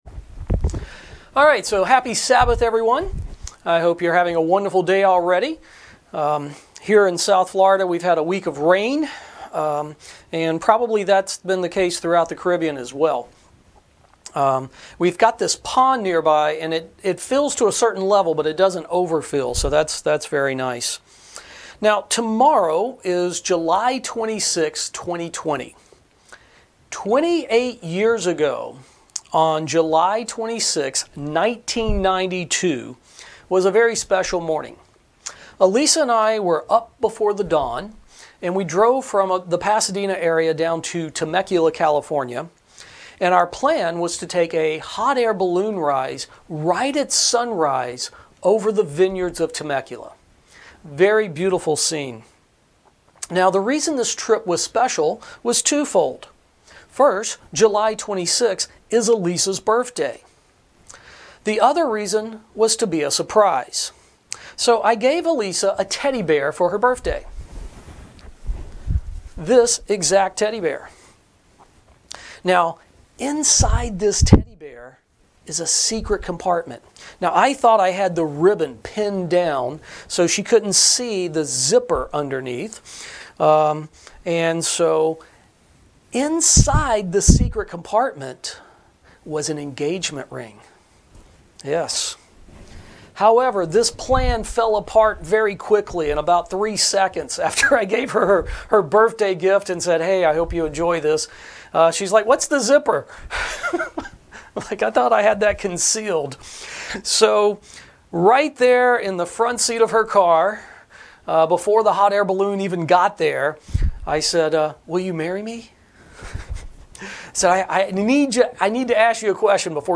Sermons
Given in Ft. Lauderdale, FL